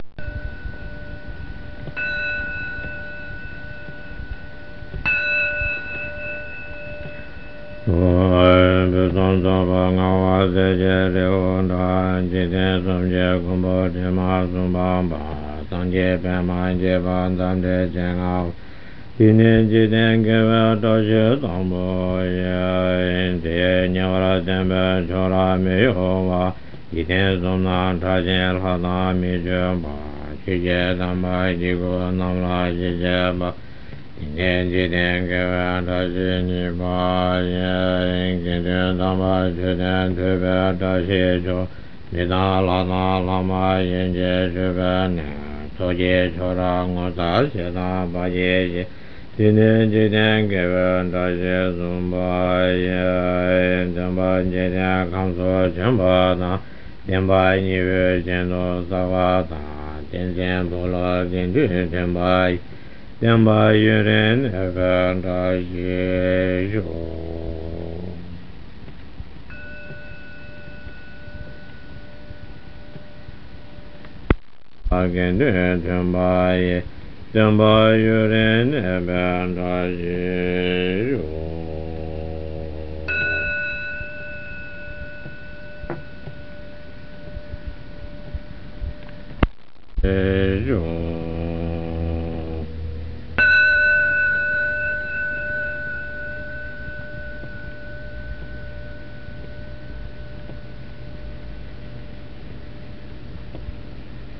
Tibetan Prayer
Chanted